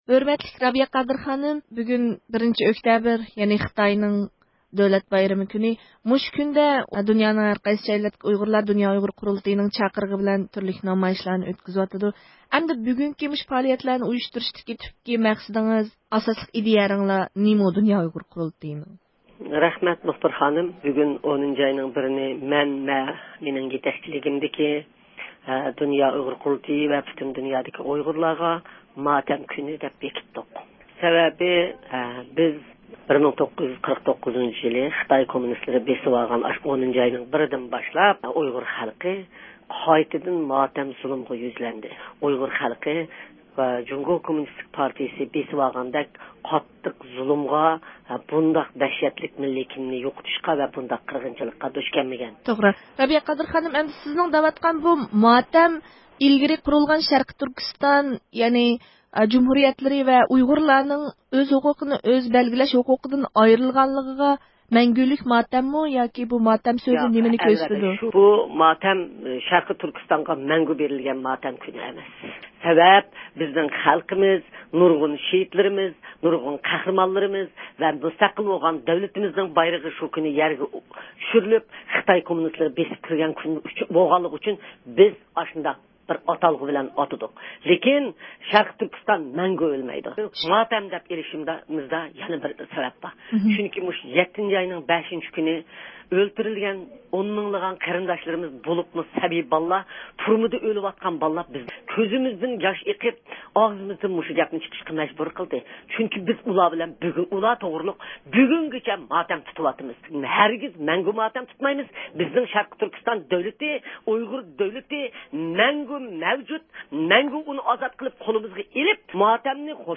بۇ مۇناسىۋەت بىلەن بىز رابىيە قادىر خانىمنى ۋاشىنگتوندىكى ئىشخانىسىدىن زىيارەت قىلدۇق.